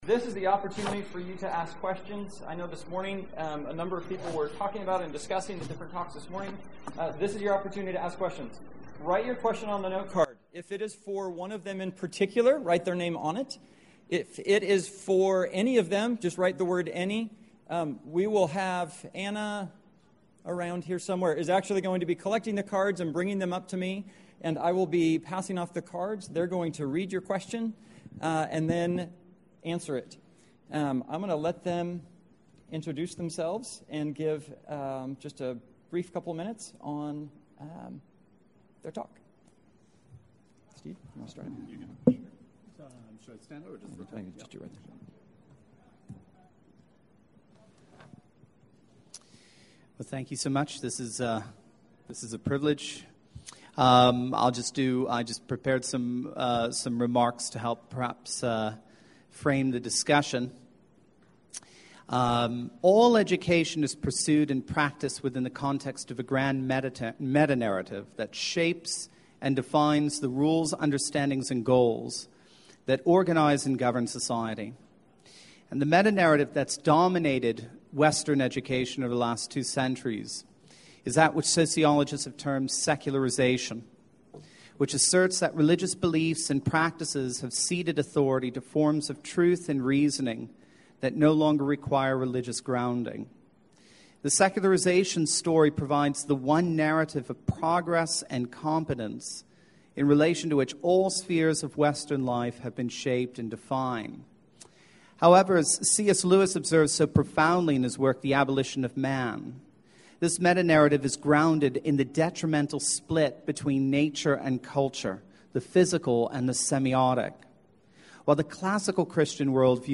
The Paideia of God as a Model for Curriculum Integration – Panel Discussion | ACCS Member Resource Center
2014 Workshop Talk | 1:00:41 | Culture & Faith, General Classroom